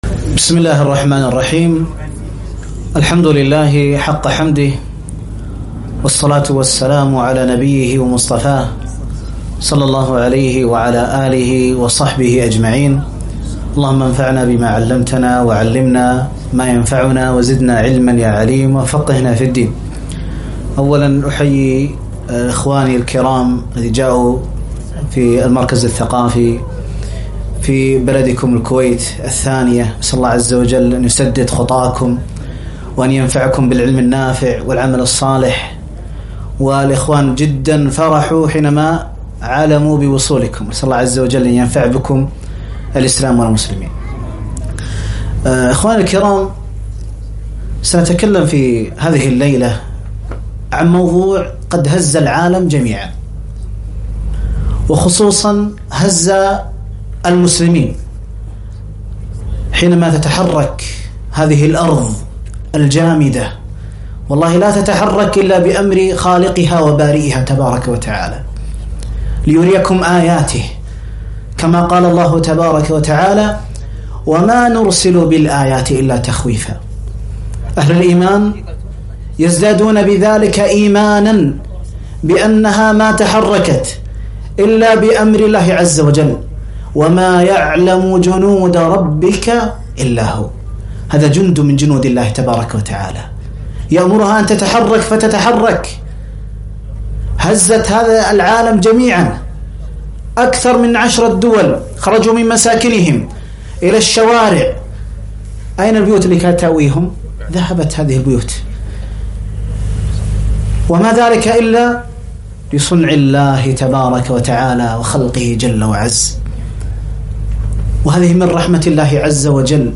محاضرة - الزلازل دروس وعبر